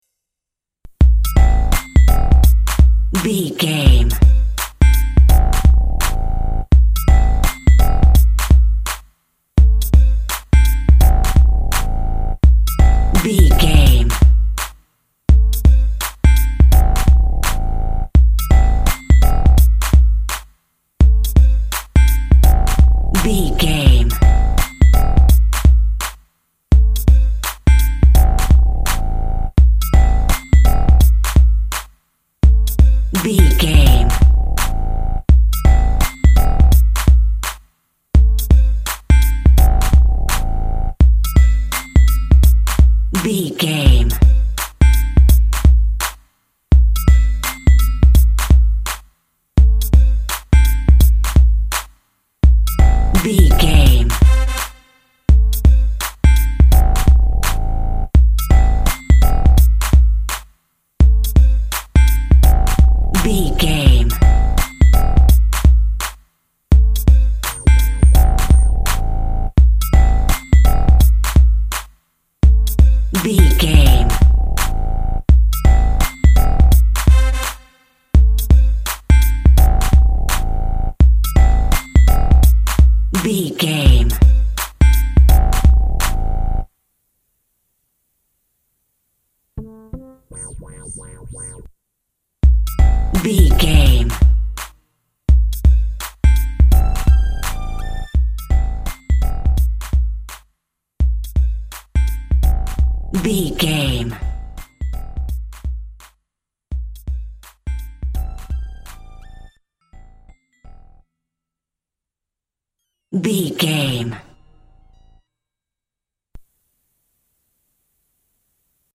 Aeolian/Minor
turntables
synth lead
synth bass
hip hop synths
electronics